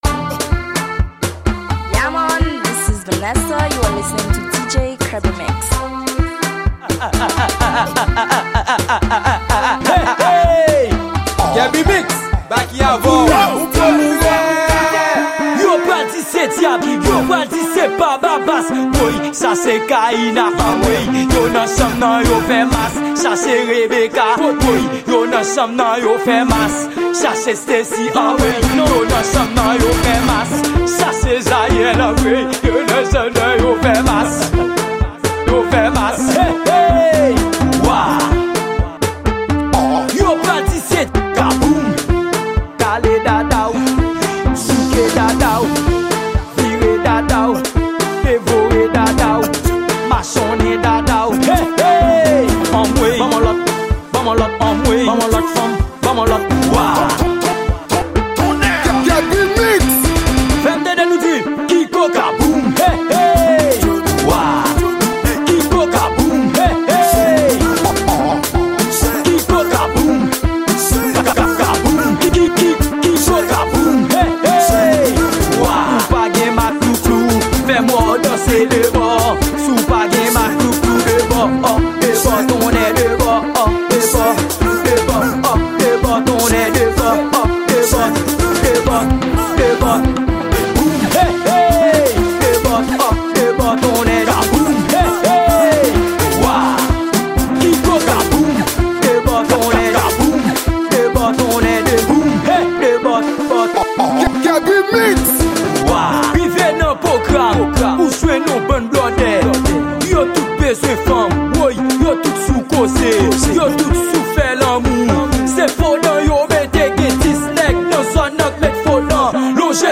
Genre:Raboday.